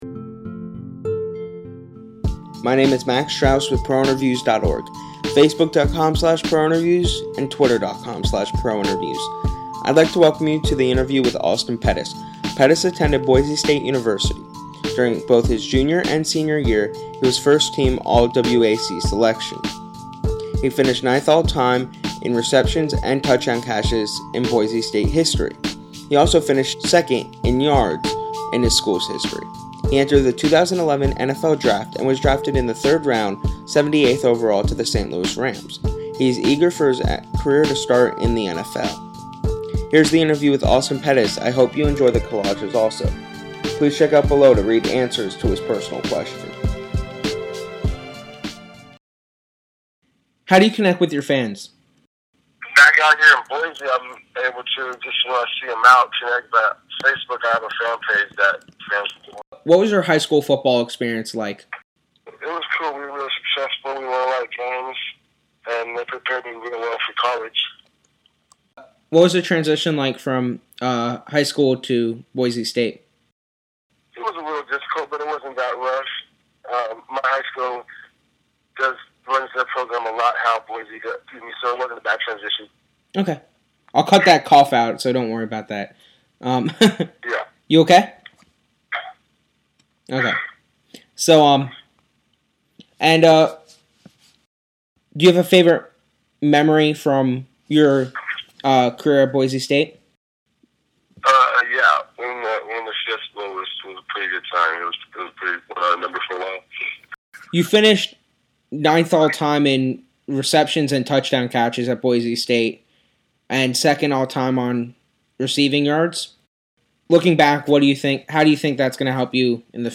interview-with-austin-pettis.mp3